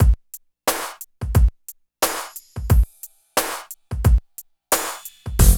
16 DRUM LP-L.wav